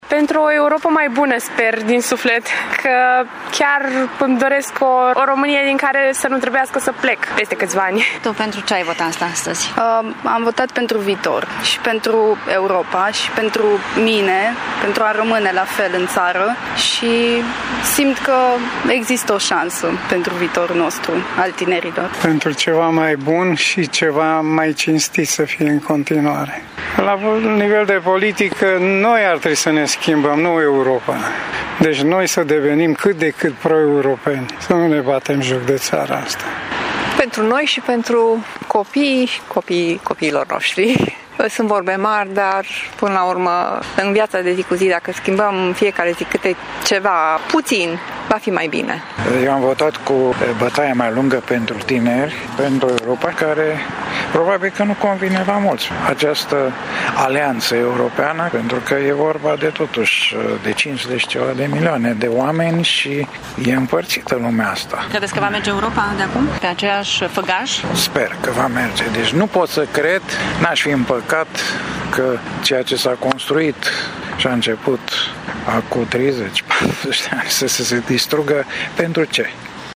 Oamenii cred că e important să ne exprimăm opțiunea în procesele electorale și să fim proeuropeni: